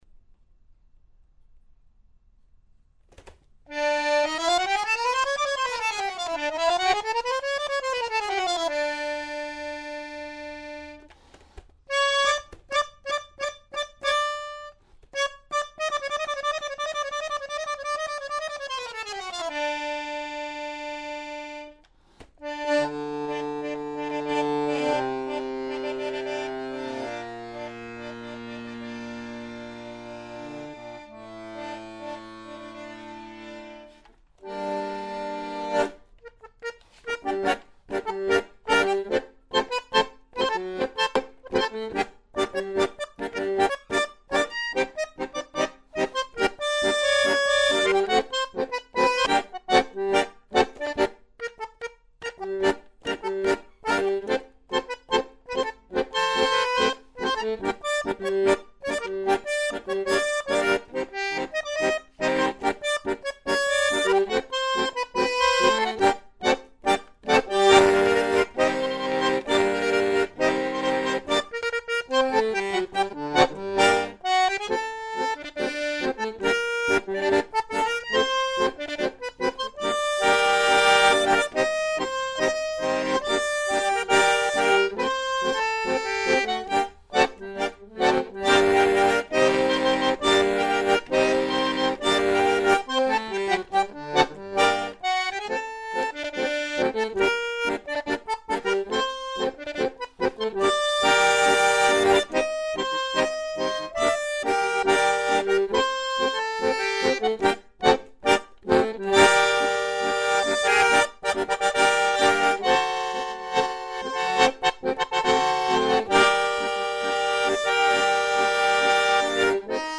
bandoneón
Su forma de tocar era estridente, con mucha potencia y con mucho ritmo.